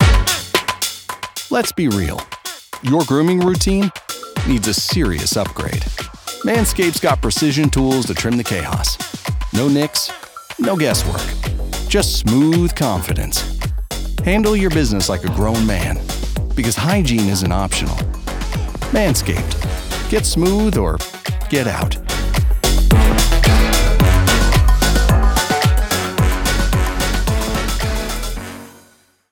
Funny · Cheeky · Confident
A comedic, confident read for lifestyle brands that don't take themselves too seriously.